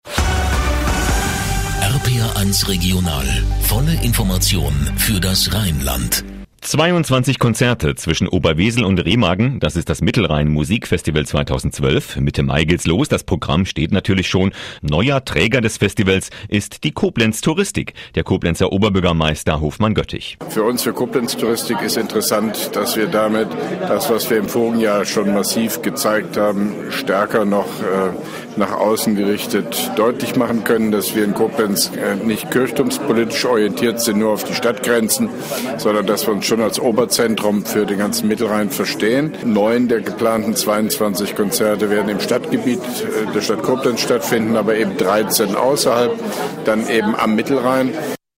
Ausschnitt: RPR1 Regional, Informationen für das Rheinland, Studio Koblenz, 20.03.2012
Mit einem Kurzinterview von OB Hofmann-Göttig